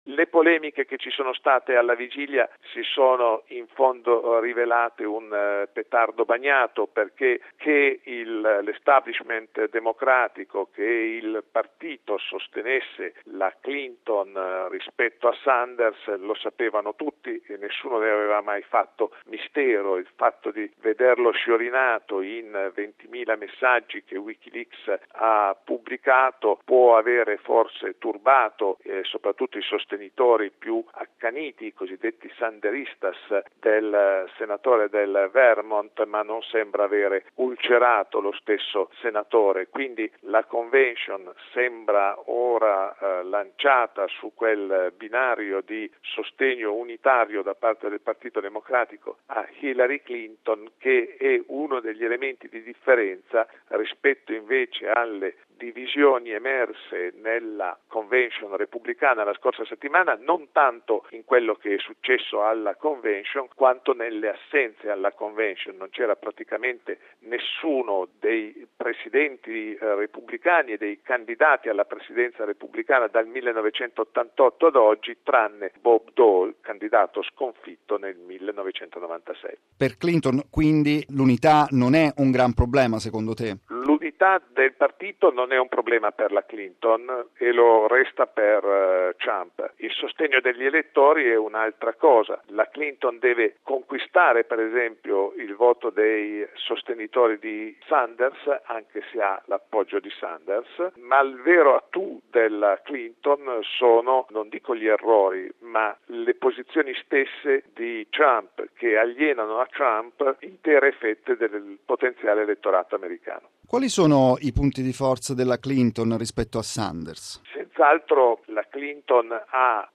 lo ha chiesto